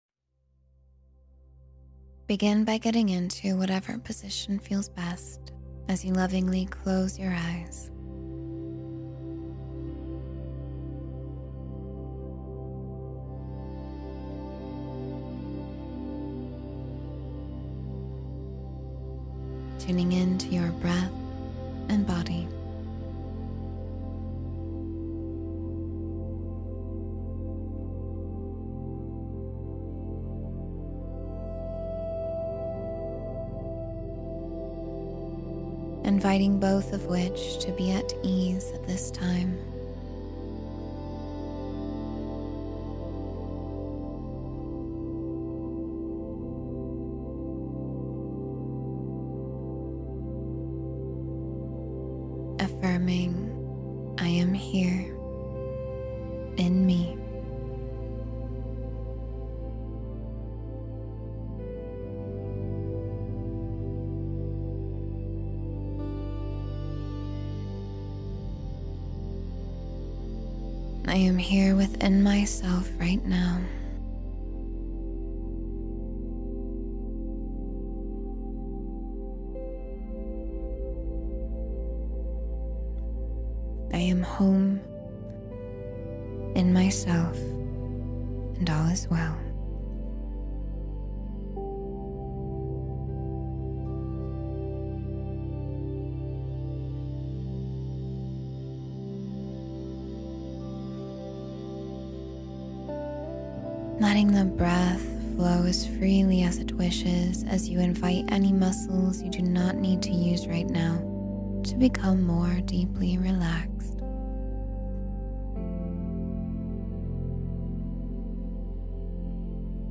Align with Your Higher Self, Now and Always — Meditation for Spiritual Alignment